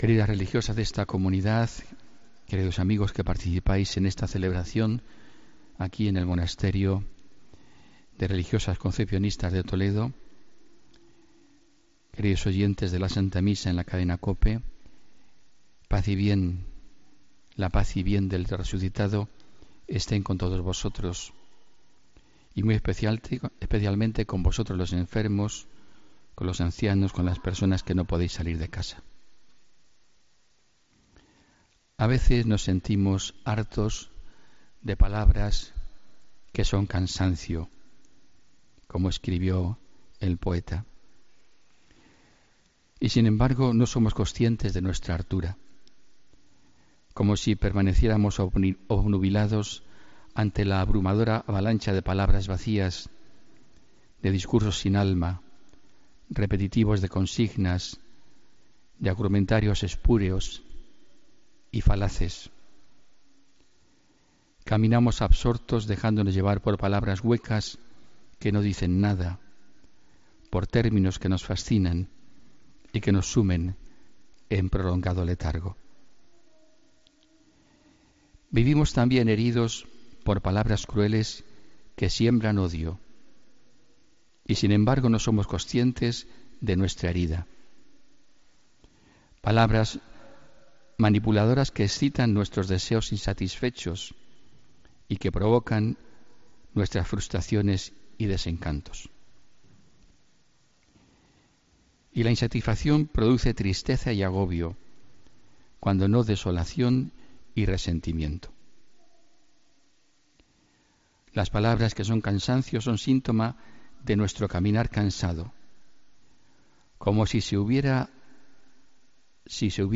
Homilía del domingo 30 de abril de 2017